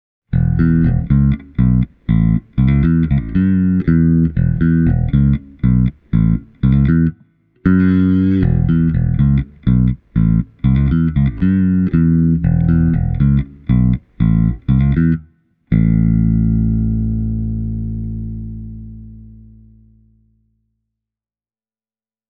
• Audio interface used: Universal Audio Volt 2
Ensimmäisessä klipissä soi sormilla soitettu Jazz-basso: